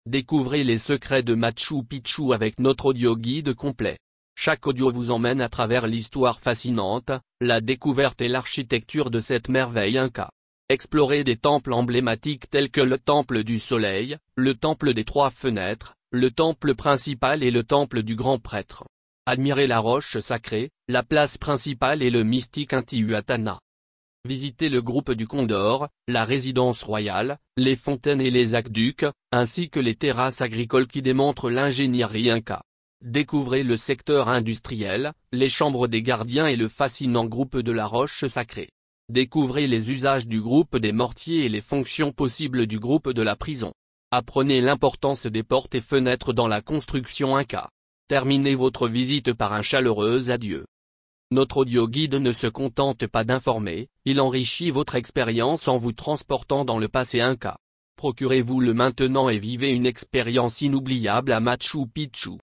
Audioguide de Machu Picchu : Explorez son Histoire avec 26 Audios Narrés
Audioguide de Machu Picchu en Français